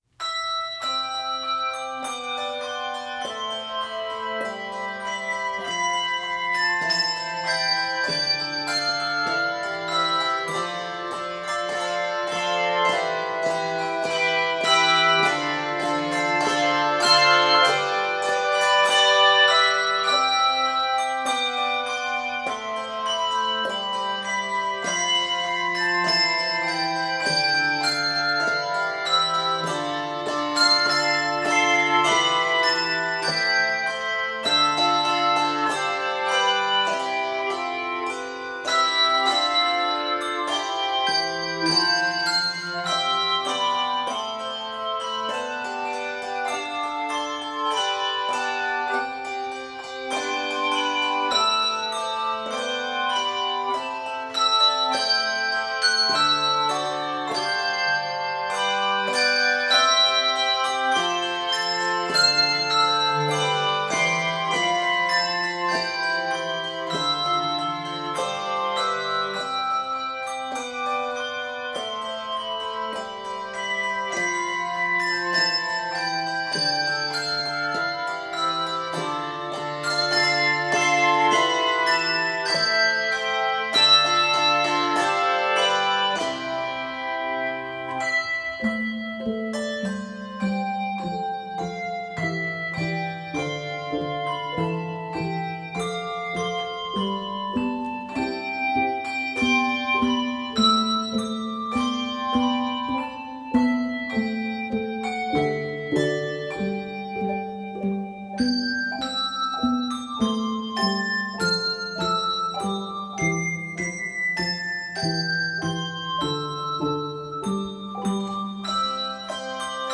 Scored for 3–5 octave handbells.